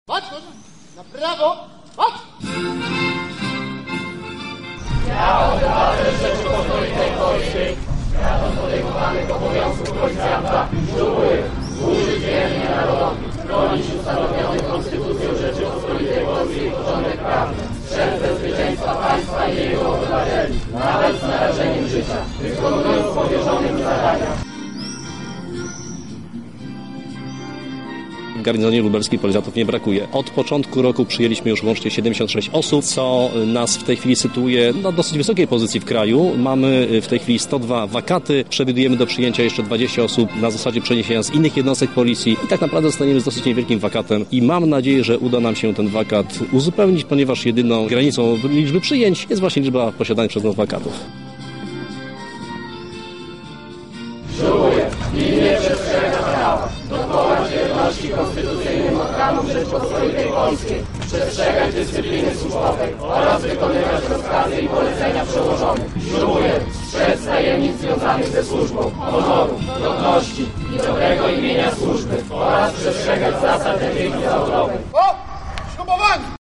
Na miejscu był nasz reporter: